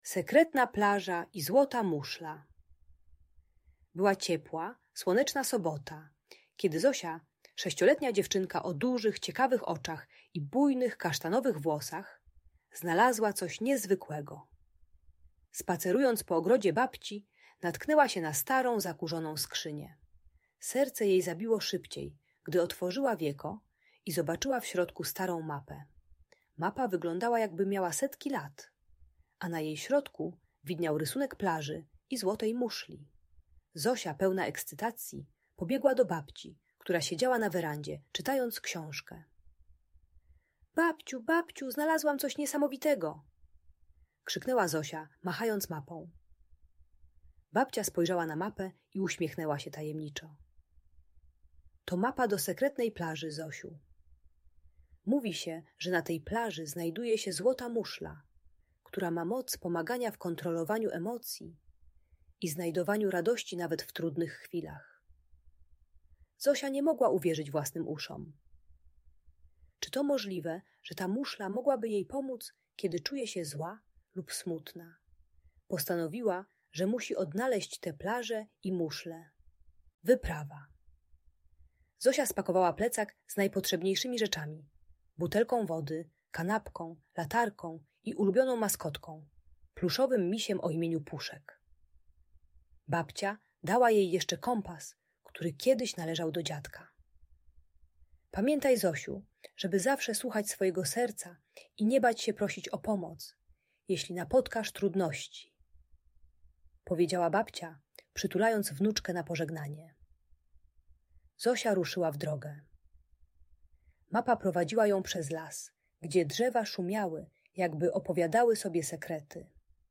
Sekretna Plaża i Złota Muszla - Bunt i wybuchy złości | Audiobajka